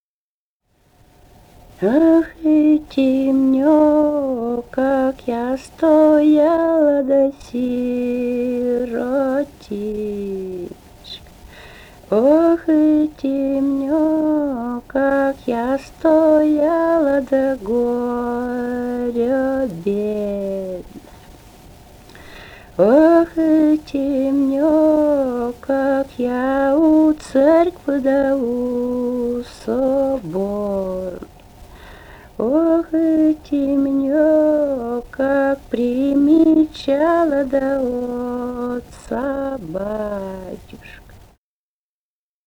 полевые материалы
Вологодская область, г. Кириллов, 1969 г. И1132-28